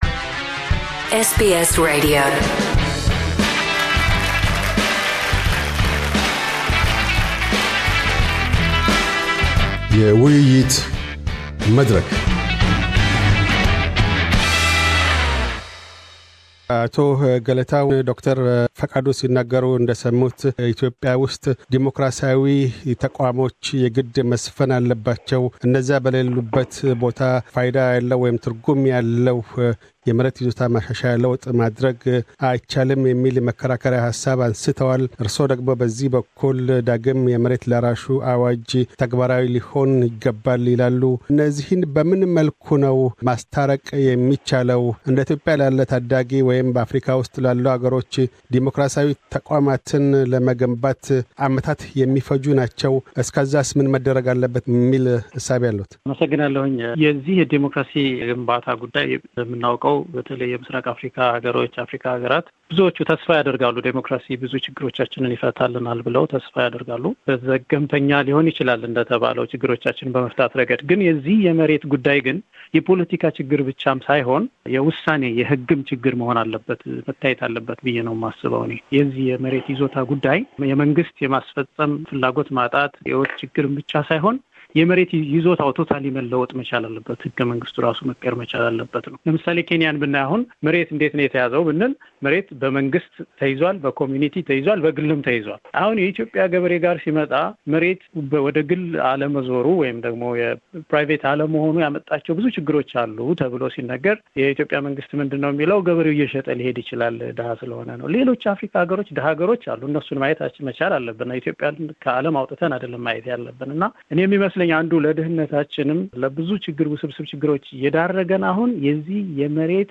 A Panel discussion: Does Ethiopia need a new land reform?